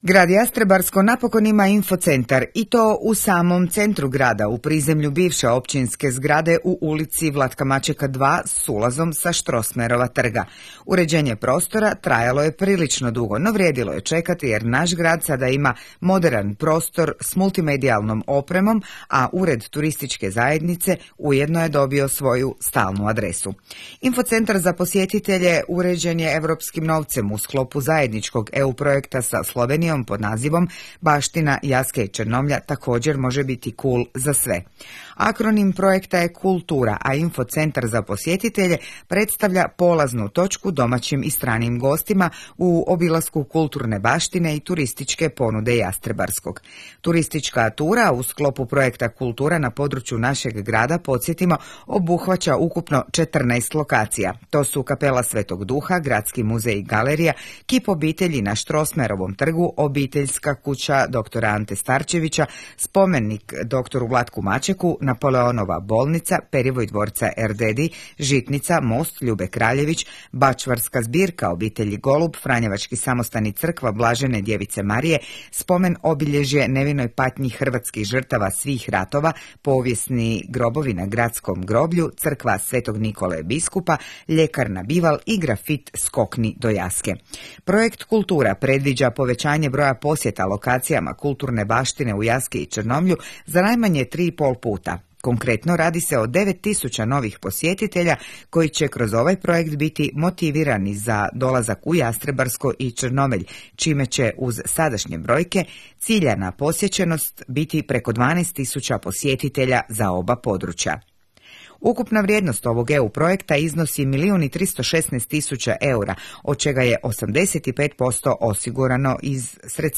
Audio prilog s otvorenja: